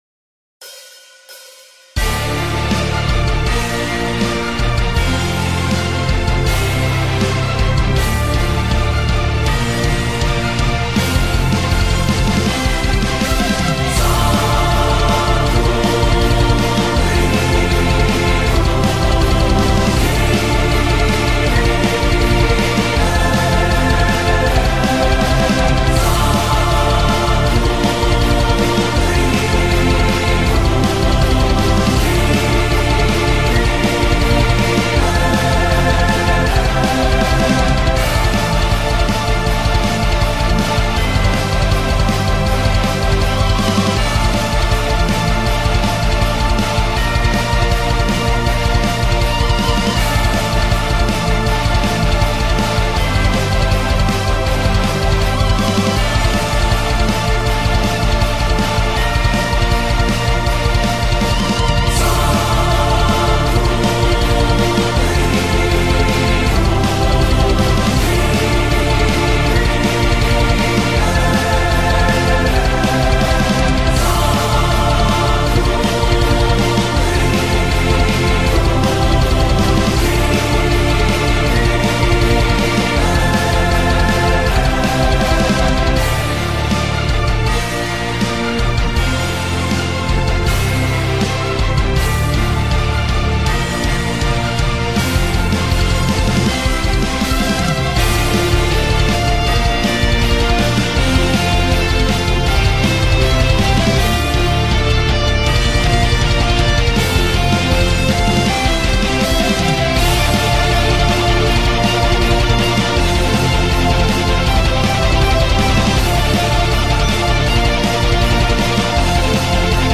Lyrics: Lol, its just gibberish.